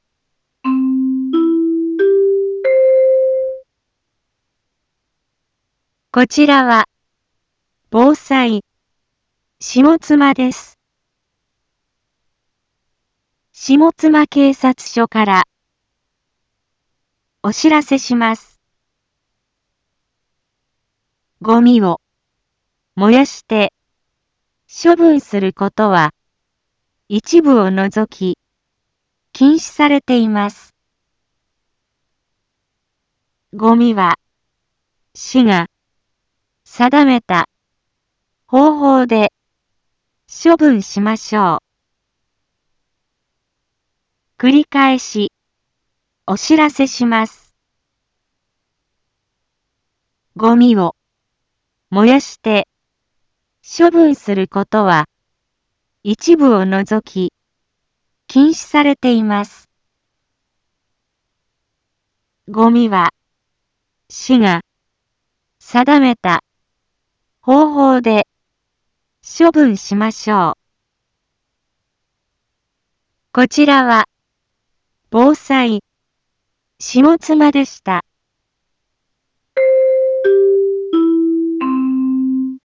一般放送情報
Back Home 一般放送情報 音声放送 再生 一般放送情報 登録日時：2024-06-25 10:01:27 タイトル：ごみの野焼き禁止（啓発放送） インフォメーション：こちらは、ぼうさい、しもつまです。